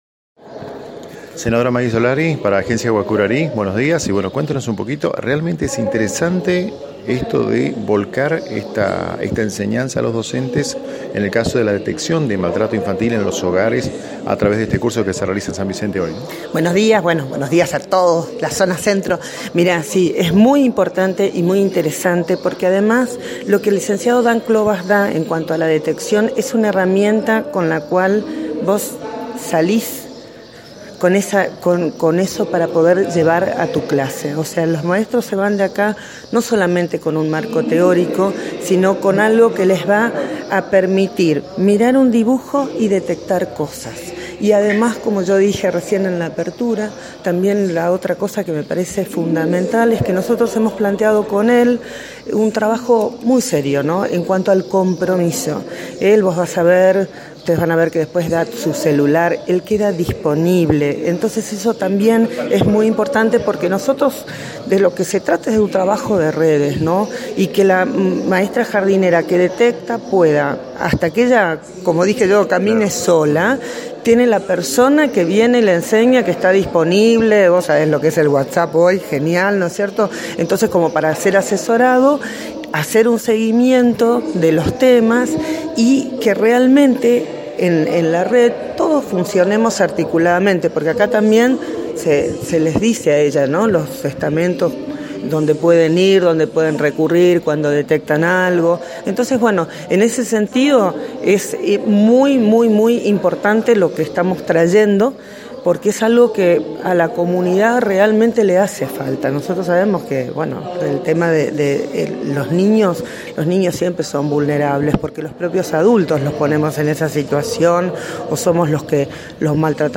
La senadora habló con ANG sobre estos talleres. http